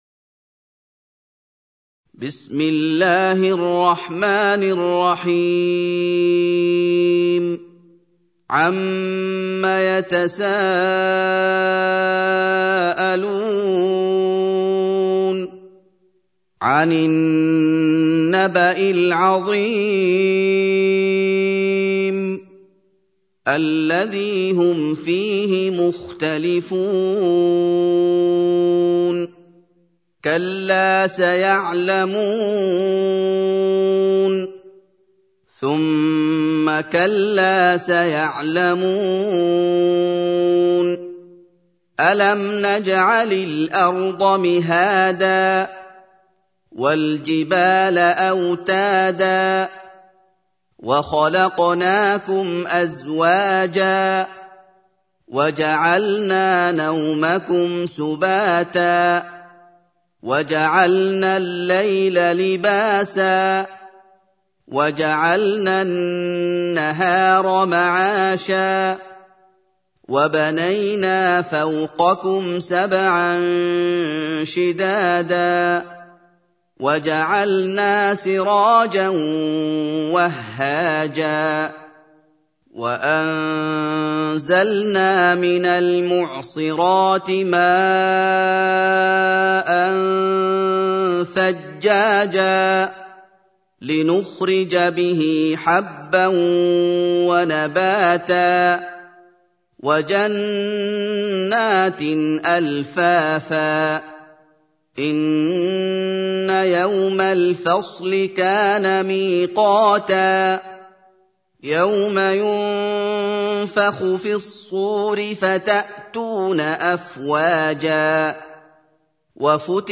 سورة النبأ | القارئ محمد أيوب